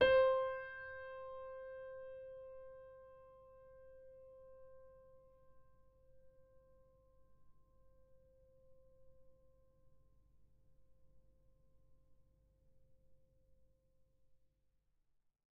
sampler example using salamander grand piano
C5.ogg